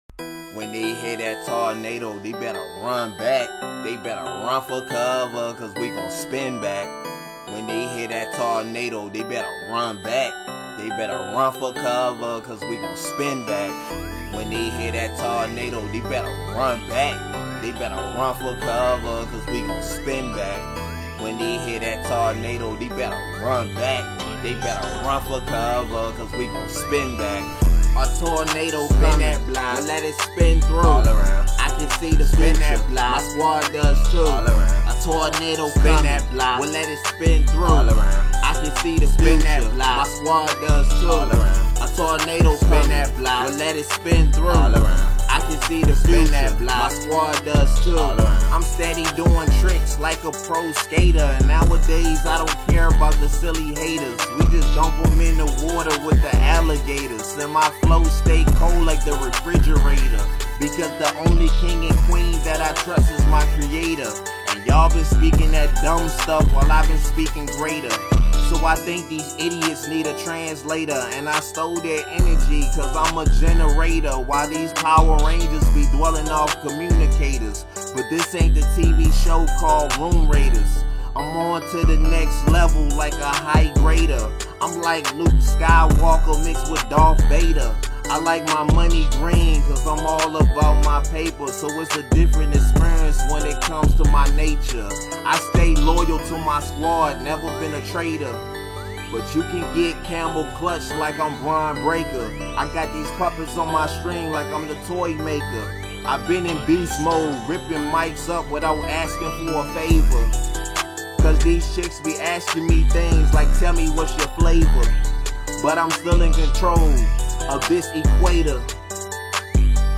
Rap
This is a hood type of joint for the streets